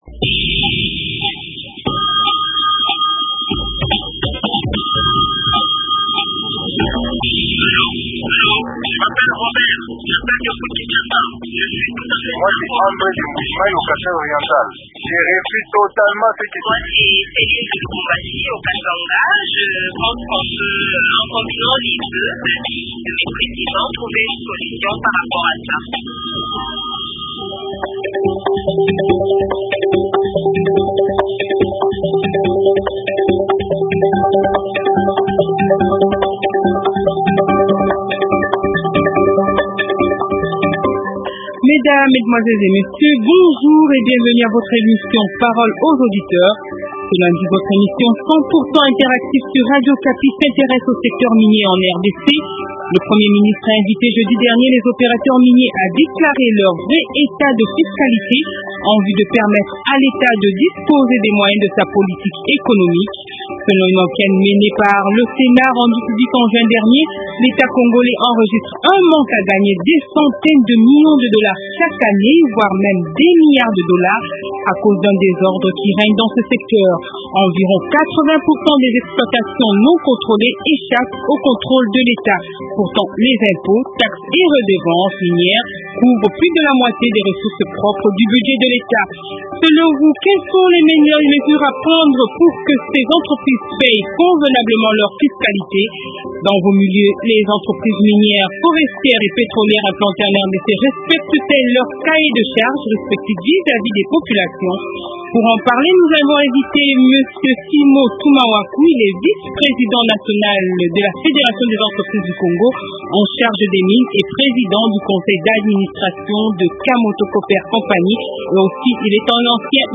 Le Premier ministre a invité, jeudi dernier, les entreprises minières à déclarer leurs vrais états de fiscalité Invité :